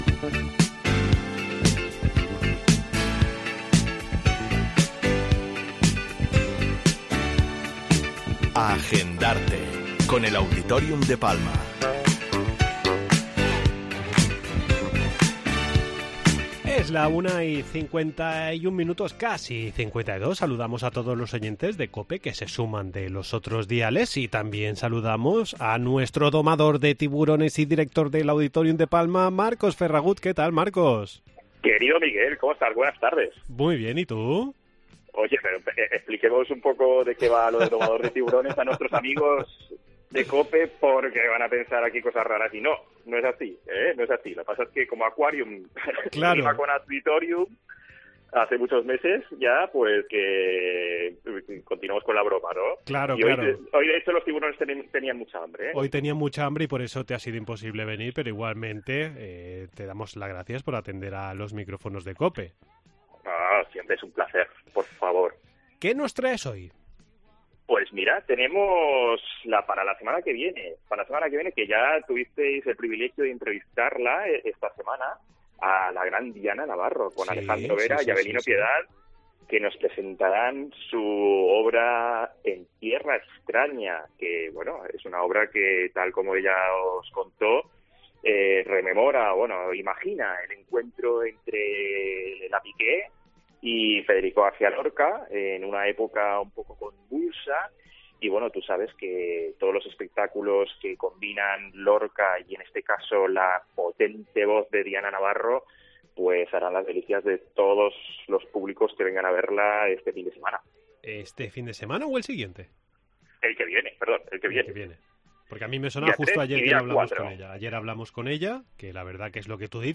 Entrevista en 'La Mañana en COPE Más Mallorca', jueves 25 de mayo de 2023.